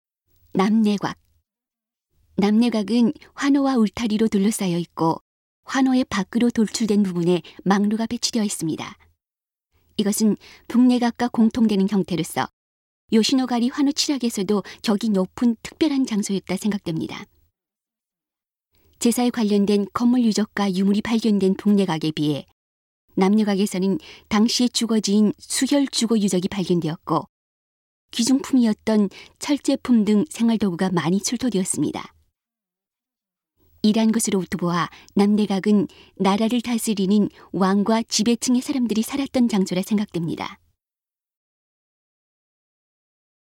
이러한 점에서 남내곽은 나라를 다스리는 「왕」과 지배층의 사람들이 살았던 장소라 추정됩니다. 음성 가이드 이전 페이지 다음 페이지 휴대전화 가이드 처음으로 (C)YOSHINOGARI HISTORICAL PARK